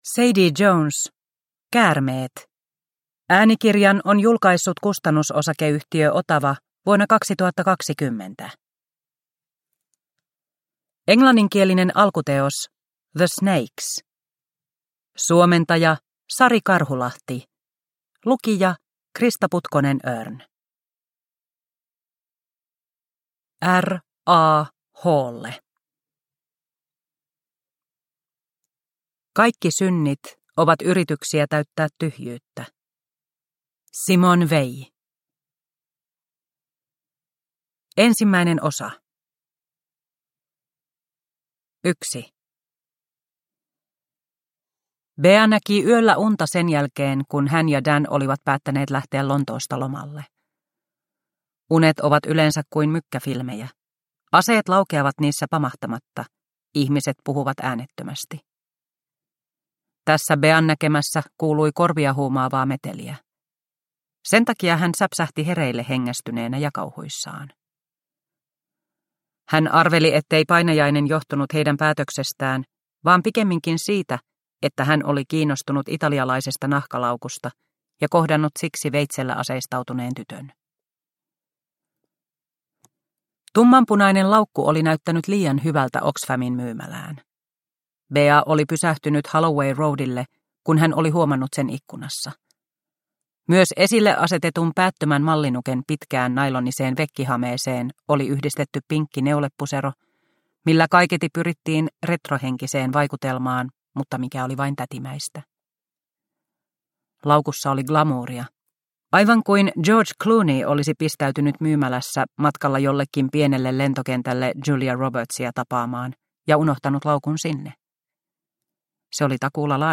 Käärmeet – Ljudbok – Laddas ner